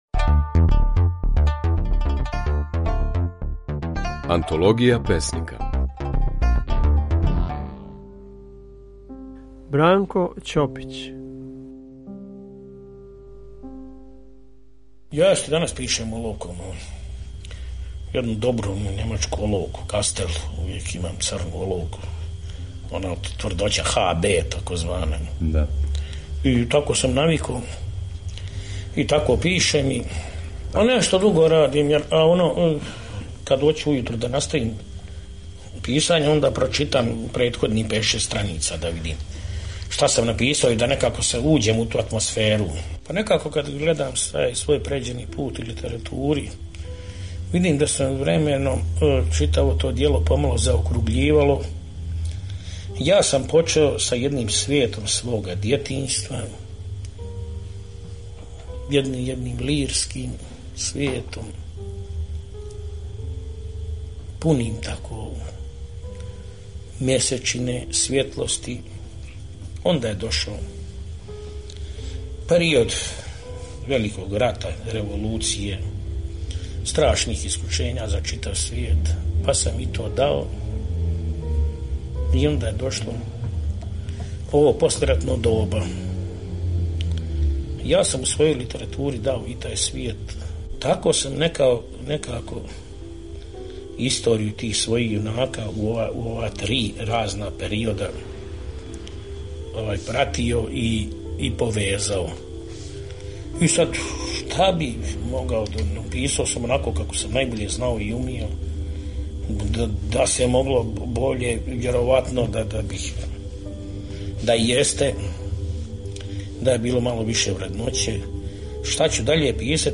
У емисији Антологија песника, можете чути како је своје стихове говорио Бранко Ћопић.